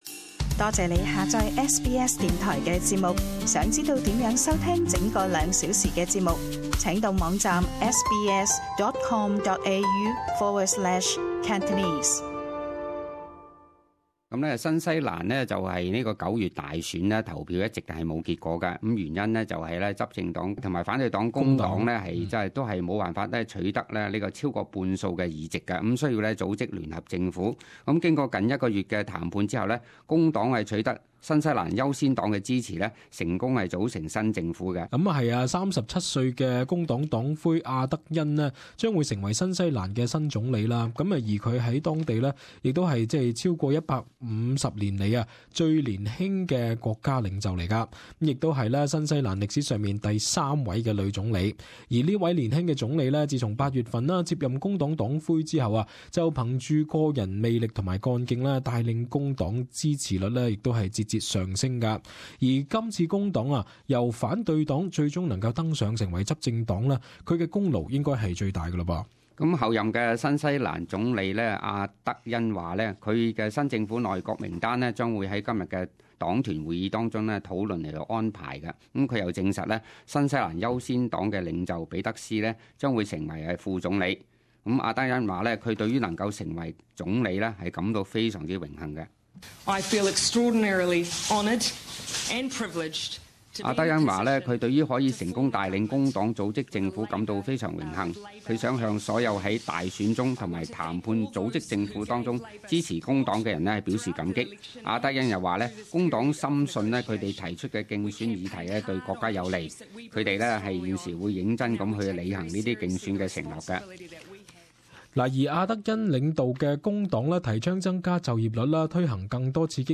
【時事報導】阿德恩成為新西蘭女總理